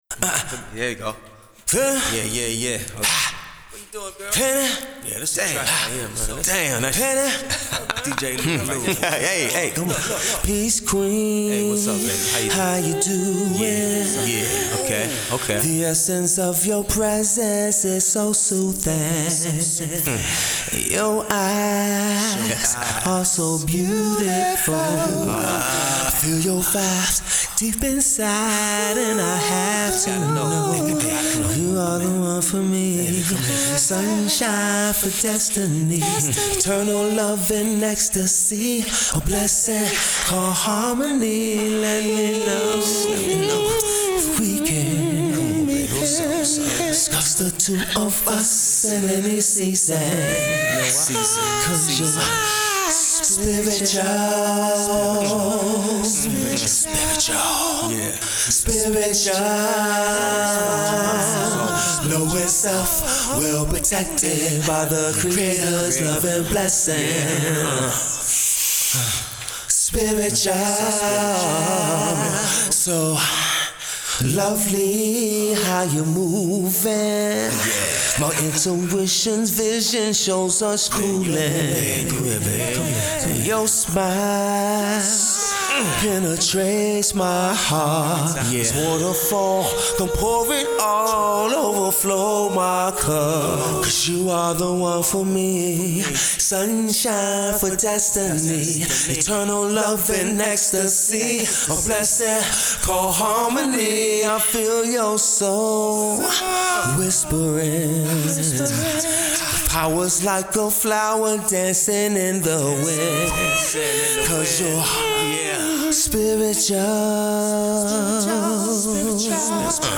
New Neo Soul R&B Heat for the ladies